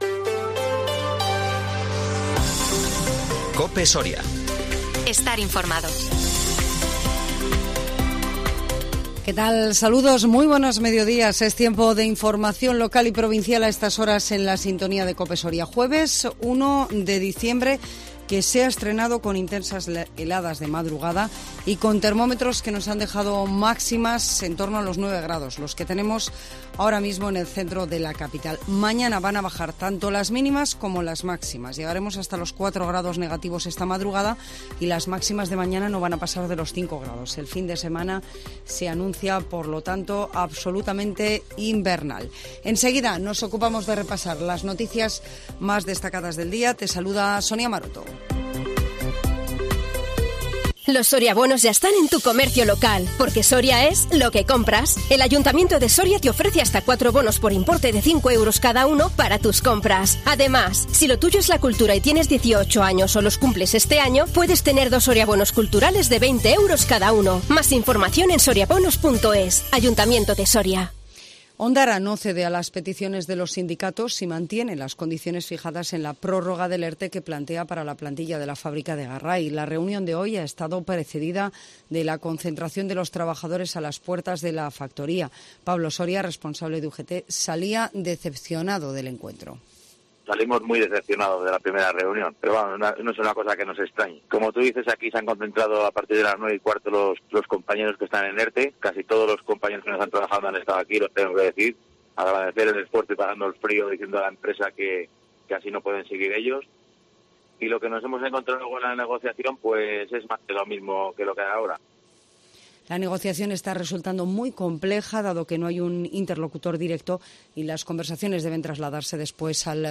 INFORMATIVO MEDIODÍA COPE SORIA 1 DICIEMBRE 2022